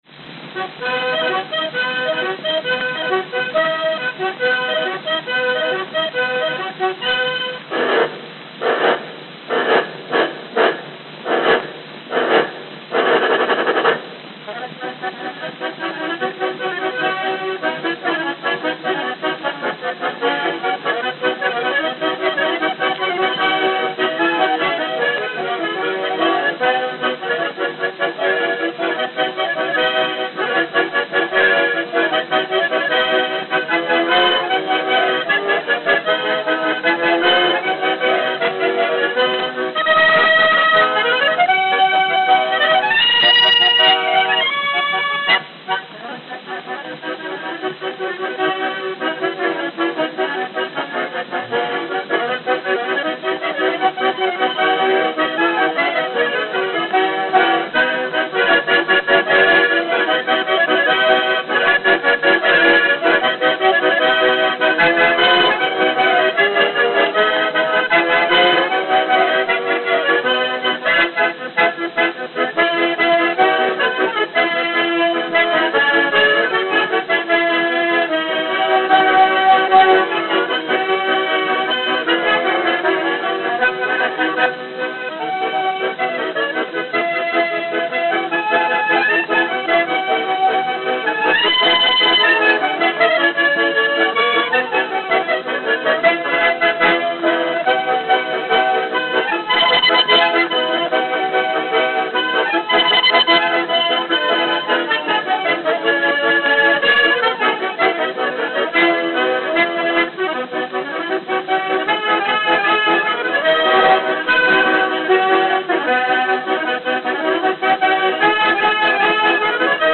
Note: Played at 78 RPM.